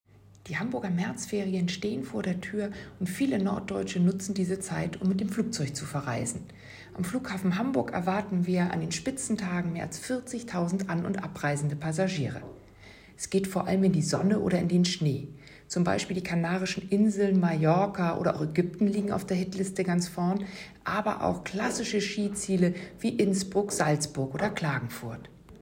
Audio-Statements